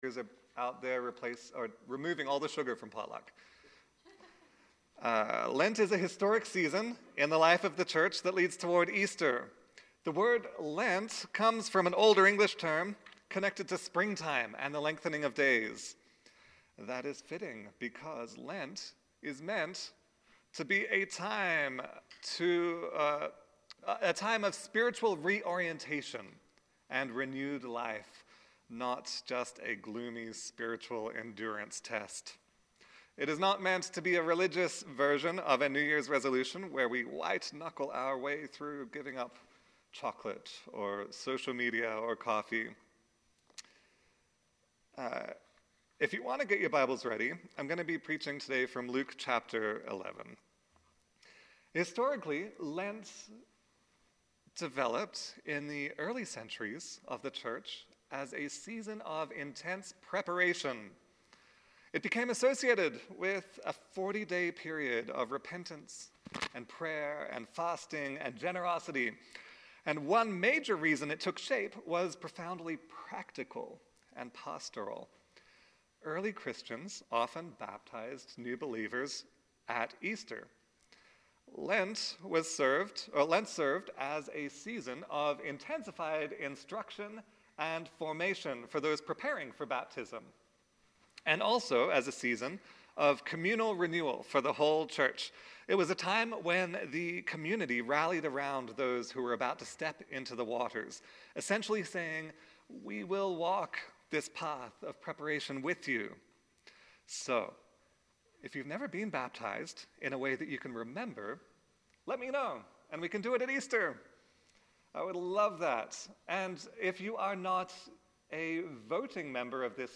Sermons | Clairmont Community Church